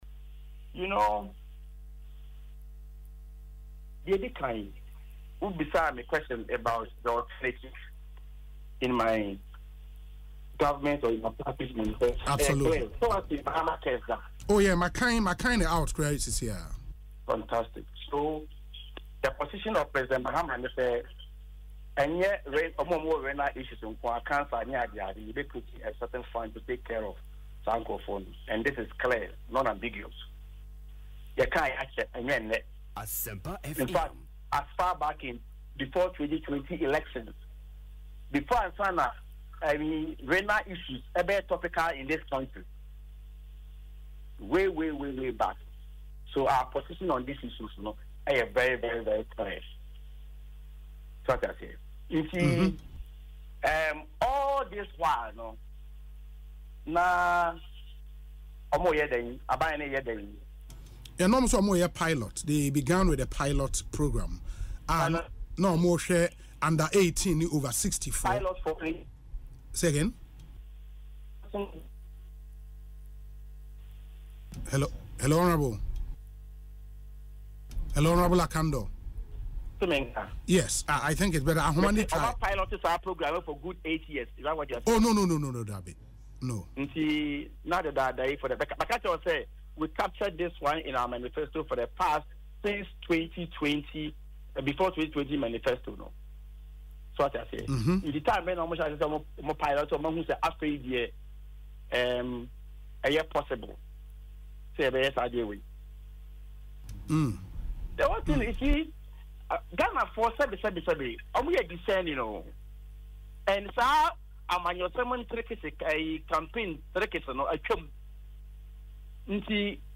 In an interview on Ekosii Sen on Asempa FM, Mr. Akandoh criticized the timing of the announcement, suggesting it is an attempt to gain votes in the December 7 election.